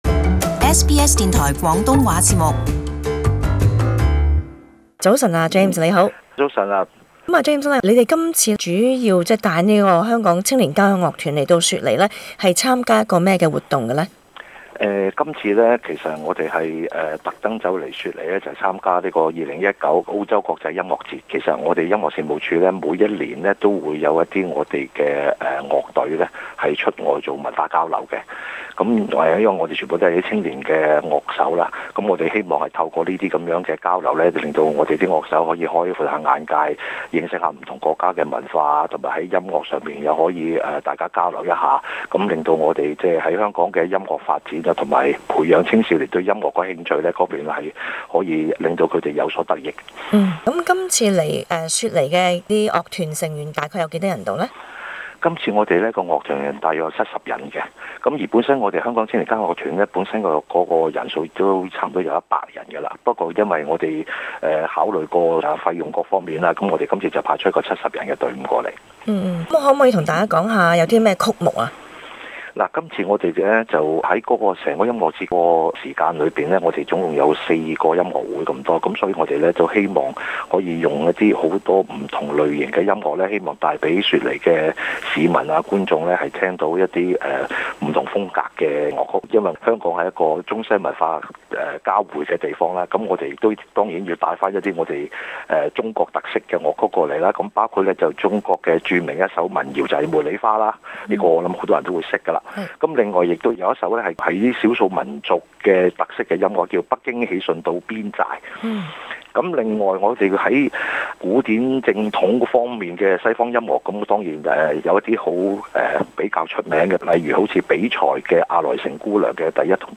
【社區專訪】香港青年交響樂團參加雪梨「2019澳洲國際音樂節」